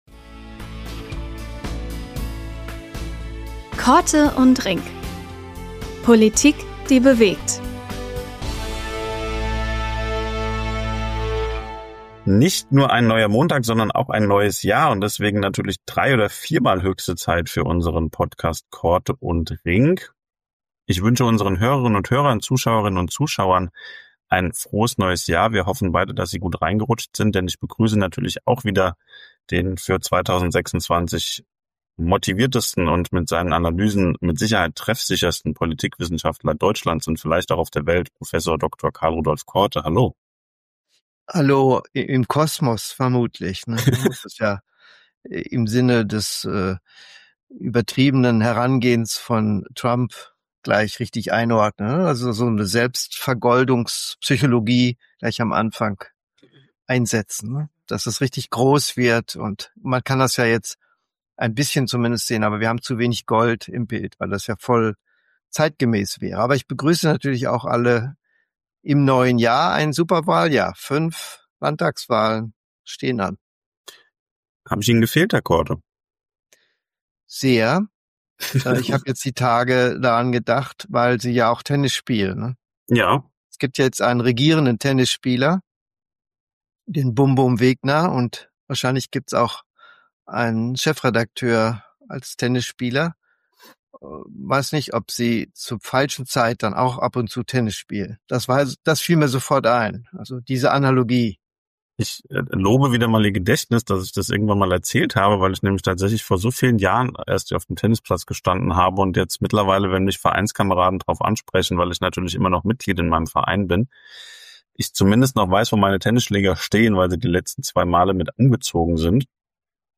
Eine Diskussion über politische Präsenz in Zeiten permanenter Beobachtung. Für den Politikwissenschaftler stehen im Wahljahr 2026 die Resilienz des Staates, Katastrophenschutz und die Frage der Bezahlbarkeit für die Bürger im Zentrum des Wahlkampfes.